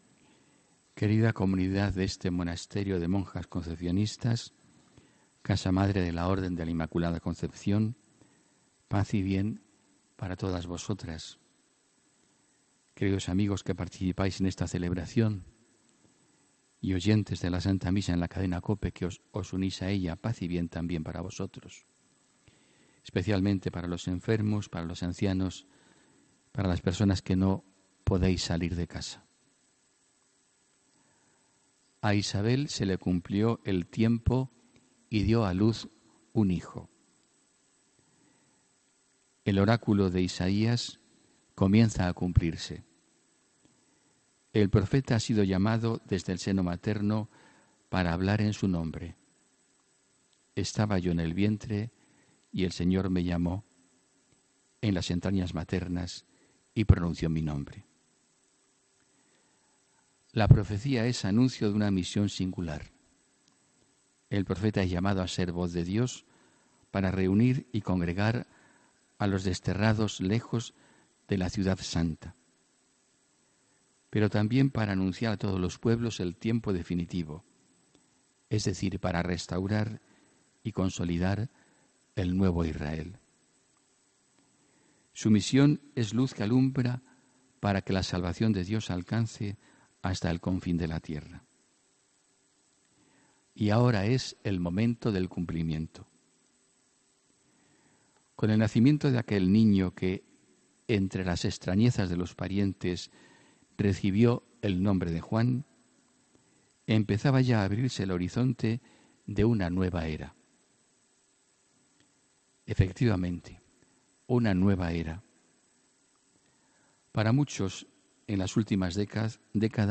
HOMILÍA 24 JUNIO 2018